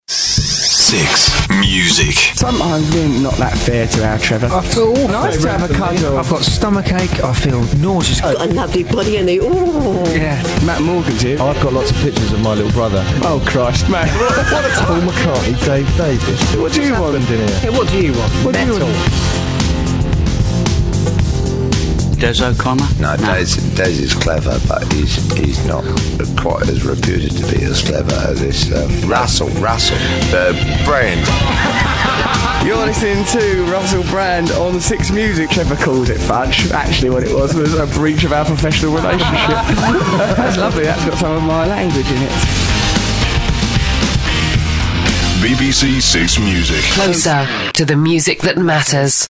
This early preview copy was found somewhere, which demonstrates the essence of the piece, but unfortunately it hasn't been mixed in a decent environment, nor has the compressor been adjusted for a more BBC-friendly response, so it isn't a very stable soundscape, still sounds a bit cluttered, and the EQ is atrocious.
I used Live 5 to produce a backing track which combines three different songs, one for each presenter, in a seamless montage, flanked by BBC 6Music jingles. In all, five elements were tonally and rhythmically matched. Adobe Audition was then used to cut in clips from a recent programme.